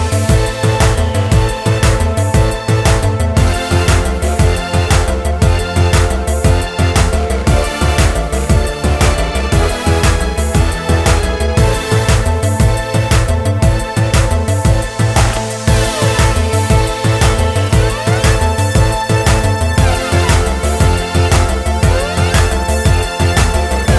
No Backing Vocals Pop